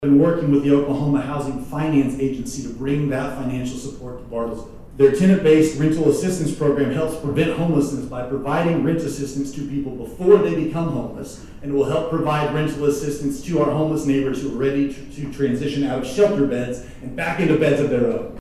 Ward 4 City Councilor and Unsheltered Homeless Task Force Co-chair Aaron Kirkpatrick
made the announcement Wednesday during the Helping Organization Summit.
Aaron Kirkpatrick on OHFA 10-22.mp3